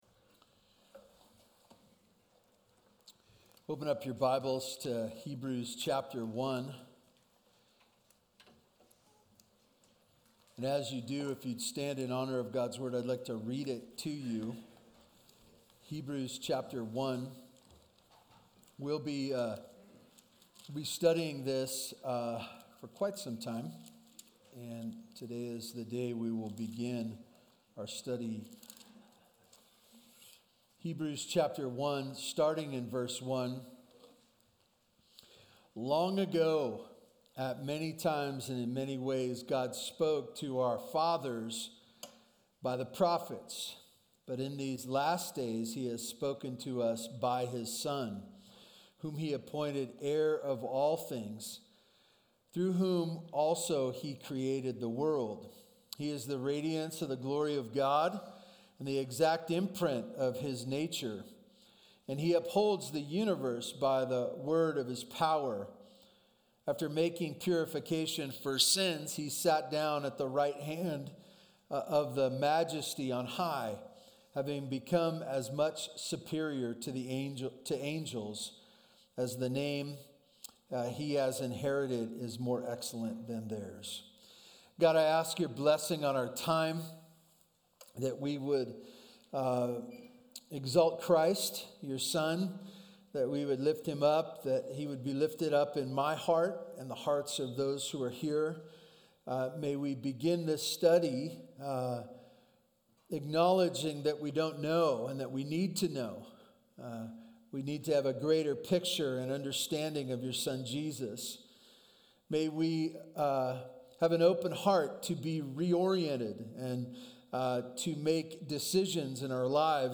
BVC Sunday Sermons